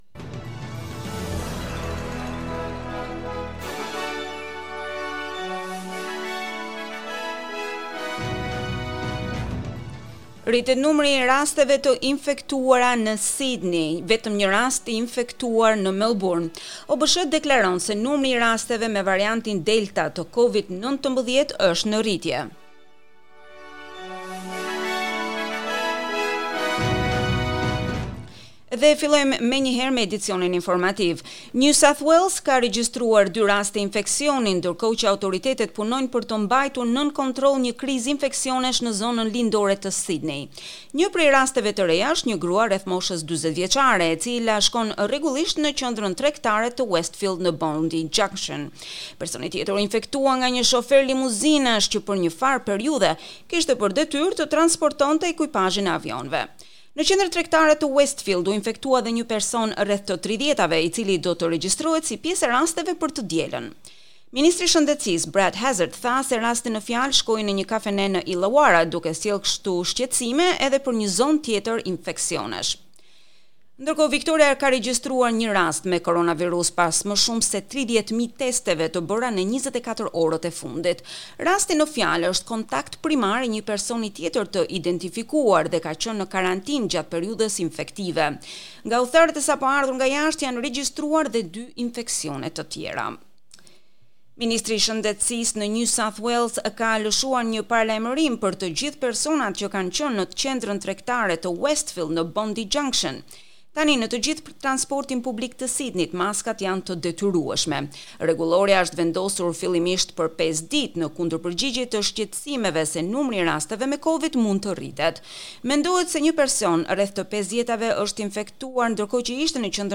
SBS News Bulletin in Albanian - 19 June 2021